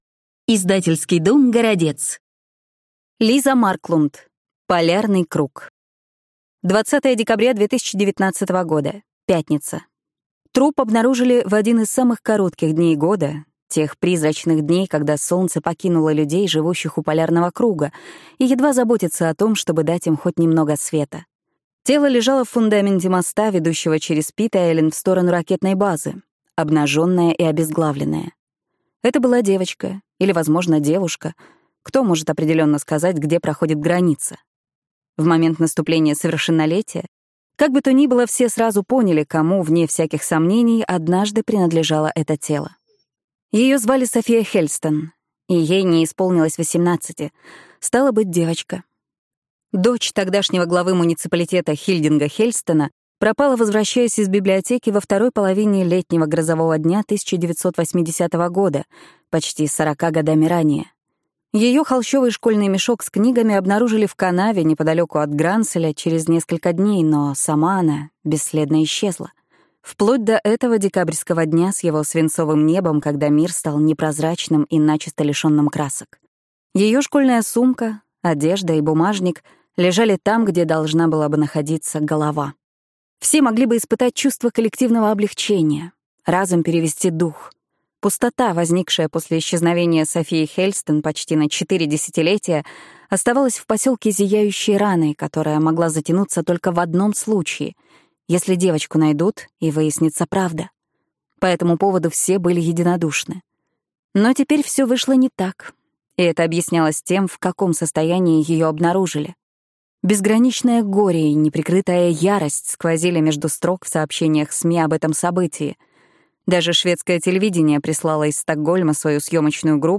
Аудиокнига Полярный круг | Библиотека аудиокниг